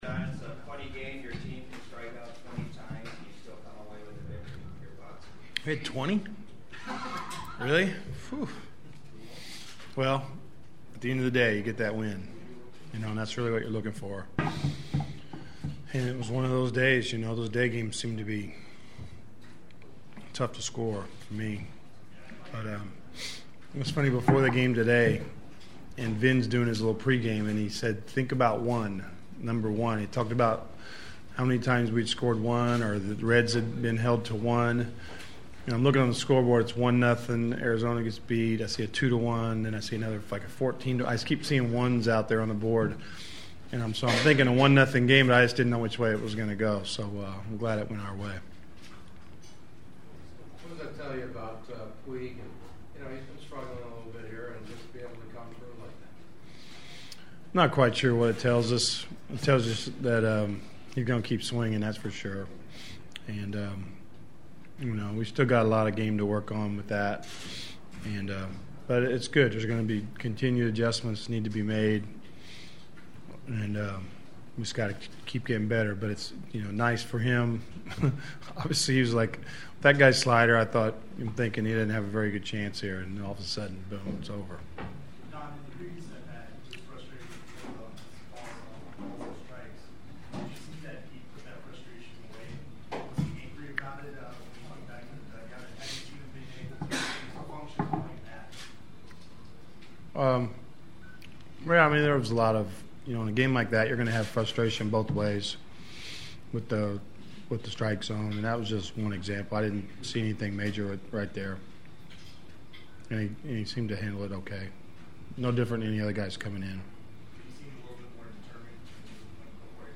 The following are my postgame clubhouse interview including some preview thoughts on the Yankees invading Dodger Stadium for 2 nights starting on Tuesday.
Manager Don Mattingly: